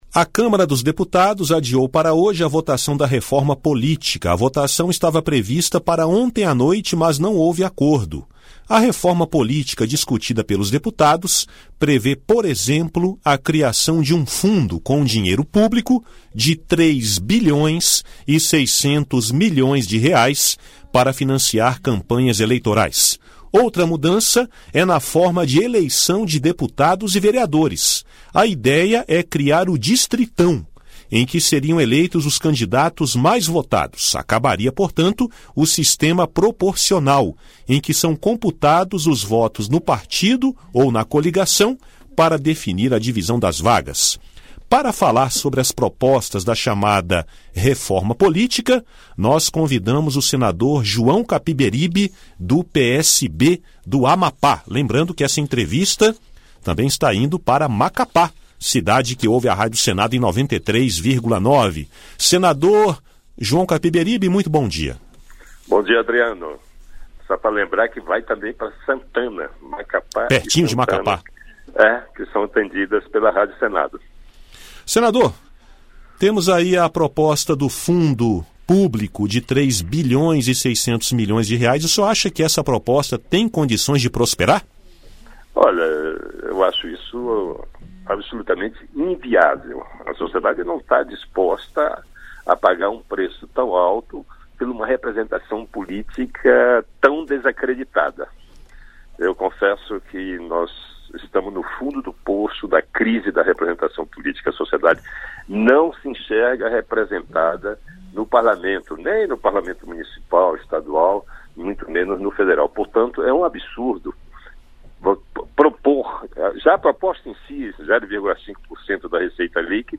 O senador João Capiberibe (PSB-AP) considera inviável a criação de um fundo de R$ 3,6 bilhões para campanhas eleitorais. Ele defende a redução drástica dos custos dessas campanhas e a adoção do financiamento individual. Em entrevista